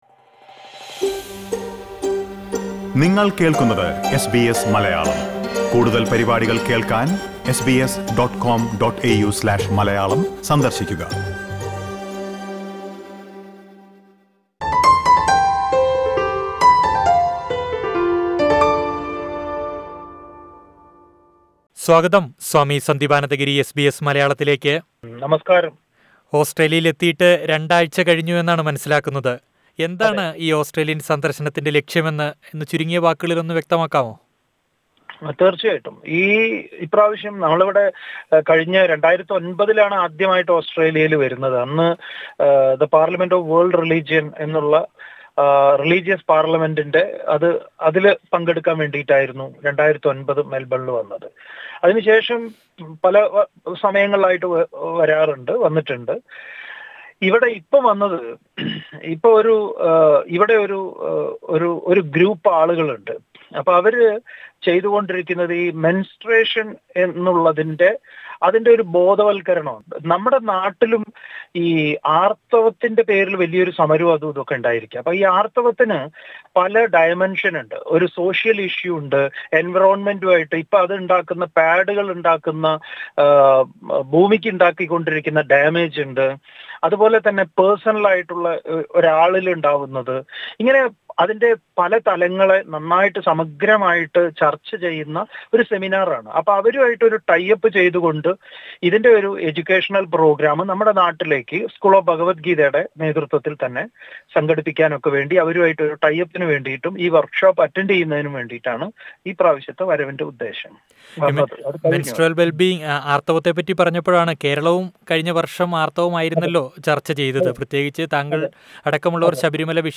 talks to SBS Malayalam during this recent Australian visit.